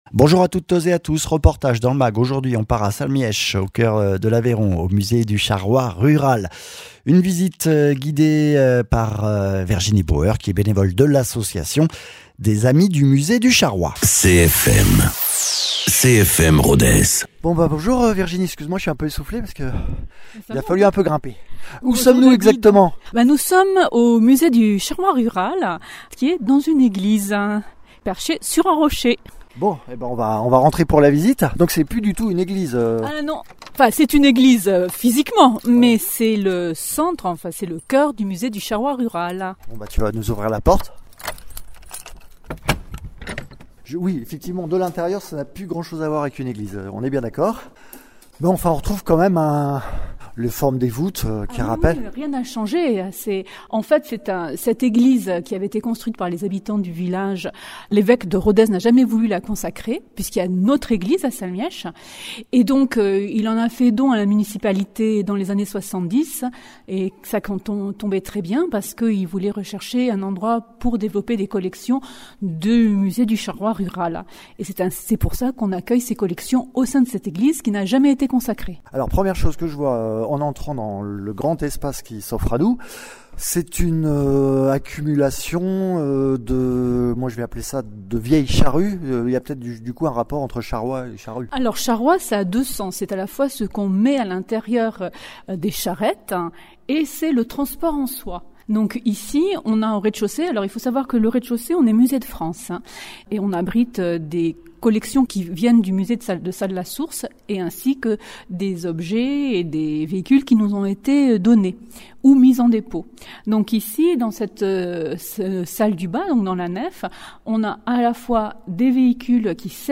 Reportage à Salmiech pour la visite du musée charroi rural et de ses 2000 pièces.
Interviews